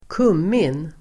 Ladda ner uttalet
kummin substantiv (används t ex i bröd), caraway; cumin [used in bread etc.]Uttal: [²k'um:in] Böjningar: kumminenDefinition: en krydda